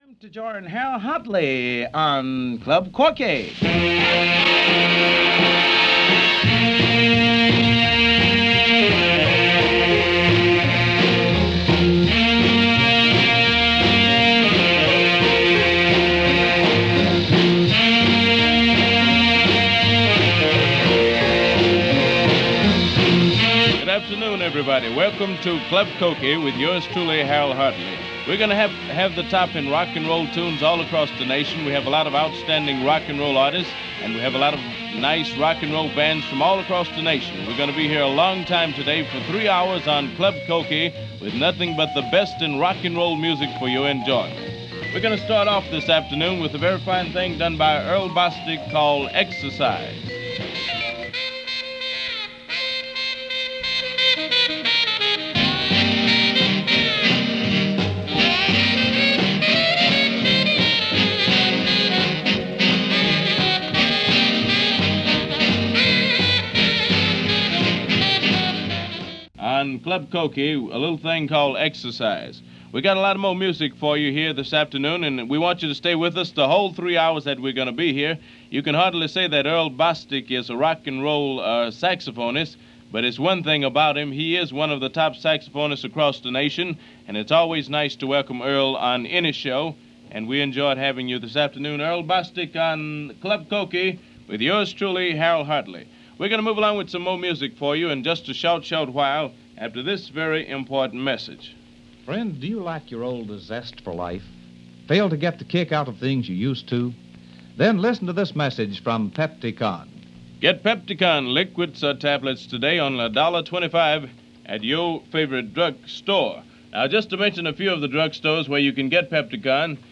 December 6, 1957 – KOKY, Little Rock Arkansas